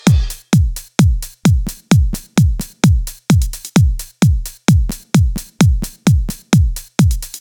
И начались проблемы - стало рвать звук на дорожках ( особенно если прописано мелкими длительностями ).
Вот пример без клепа.